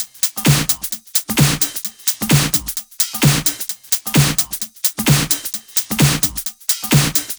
VFH2 130BPM Comboocha Kit 3.wav